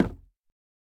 Minecraft Version Minecraft Version 1.21.5 Latest Release | Latest Snapshot 1.21.5 / assets / minecraft / sounds / block / chiseled_bookshelf / break3.ogg Compare With Compare With Latest Release | Latest Snapshot